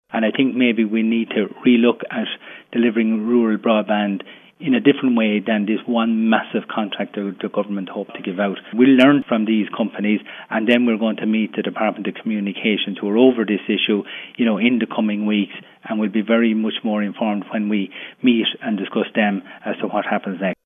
Fianna Fáil Deputy Sean Fleming says he doesn’t believe we’re currently in a competitive tendering situation: